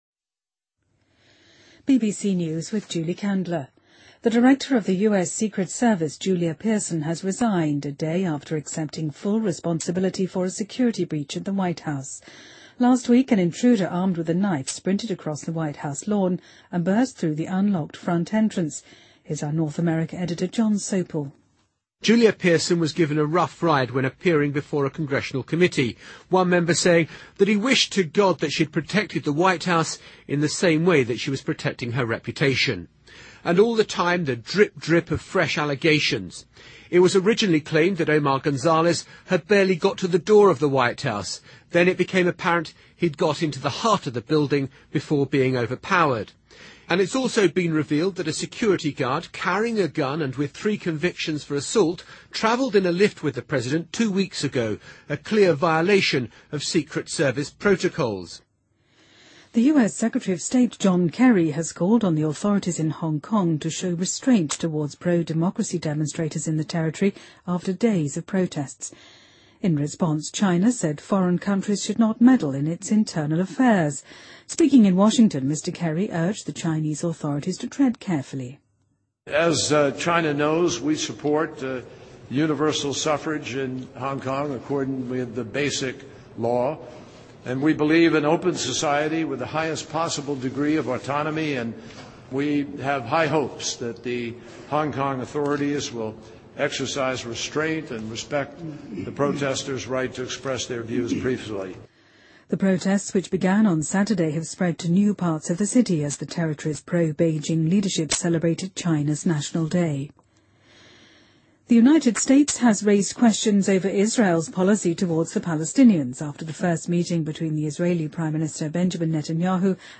BBC news:美国特工处处长朱莉娅·皮尔森辞职|BBC在线收听